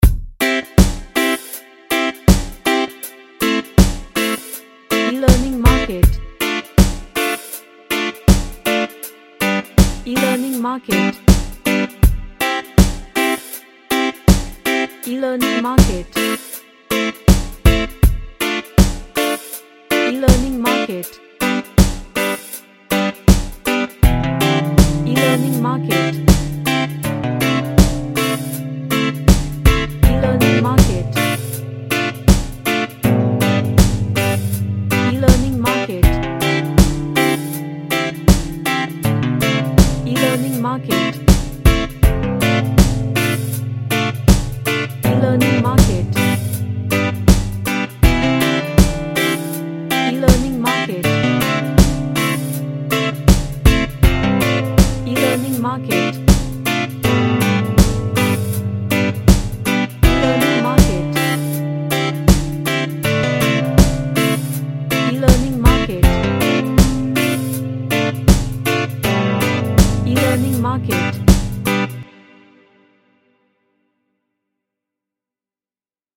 A reggae track with lots of happy vibes.
Happy